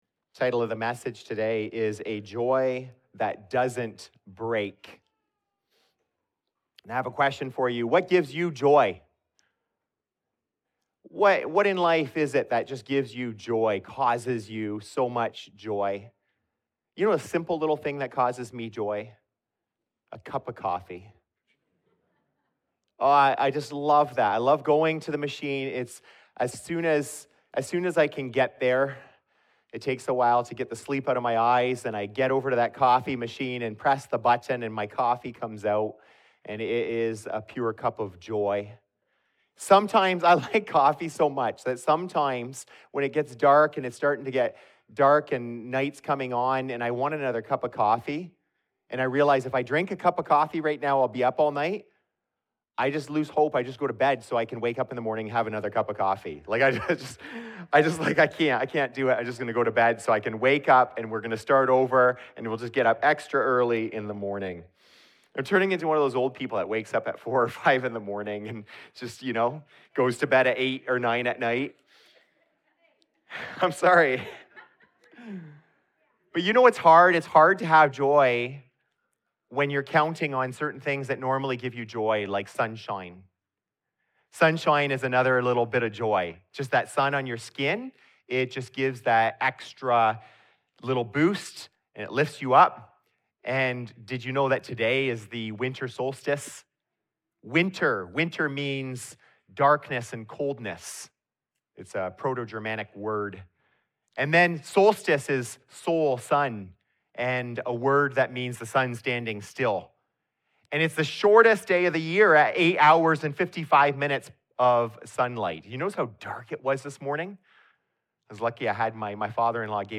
In this Advent message, we talk about a joy that isn’t fake, fragile, or seasonal.